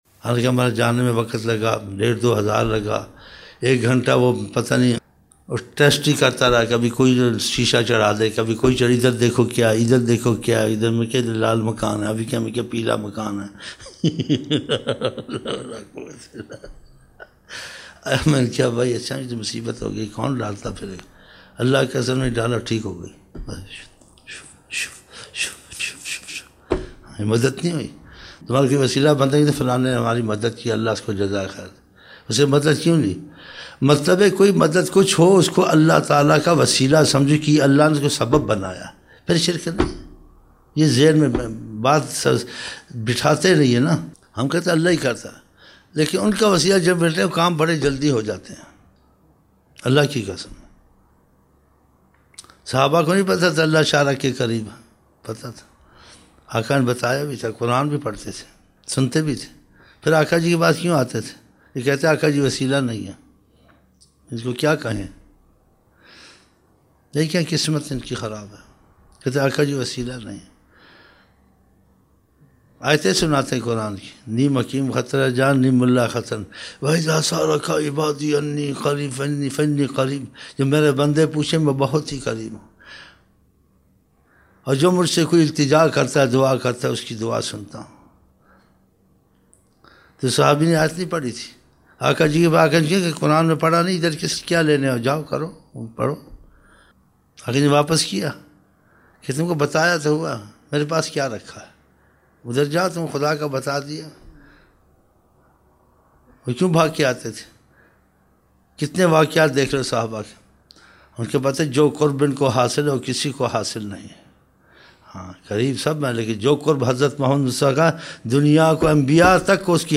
21 November 1999 - Zohar mehfil (13 Shaban 1420)